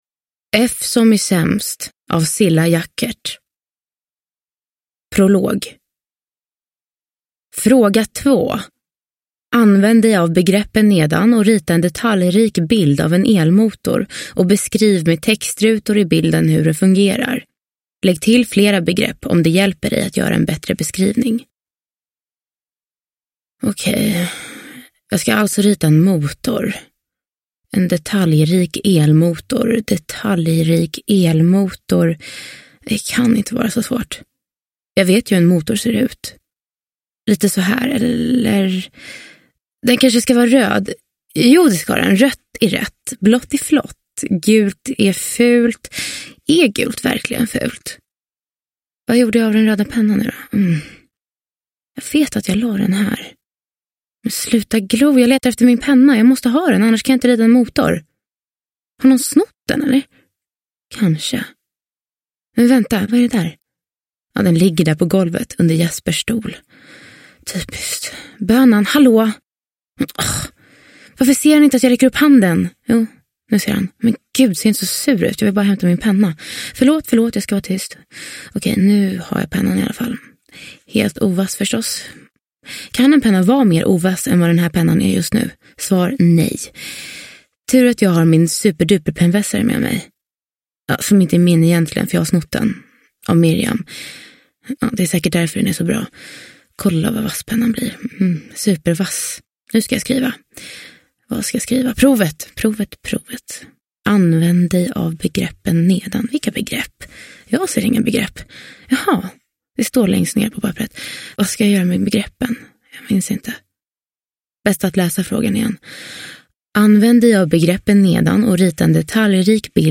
F som i sämst – Ljudbok – Laddas ner